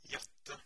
Ääntäminen
US : IPA : [ə.ˈɡri]